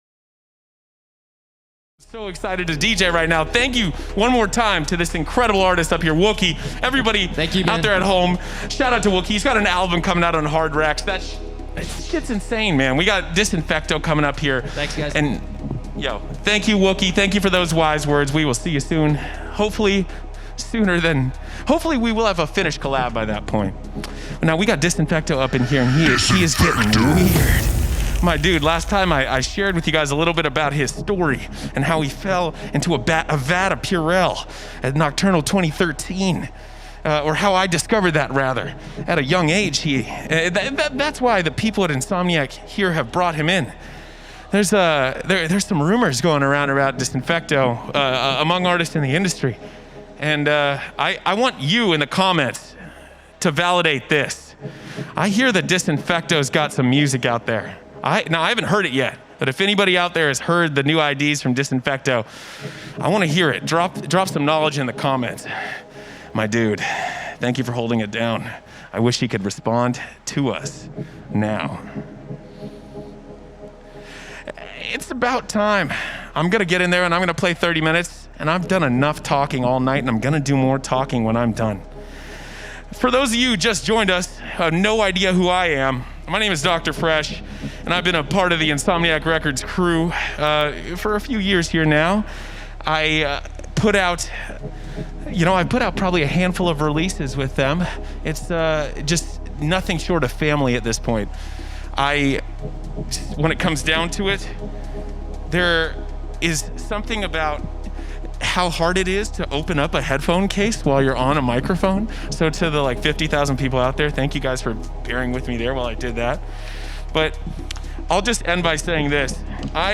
DJ Mixes and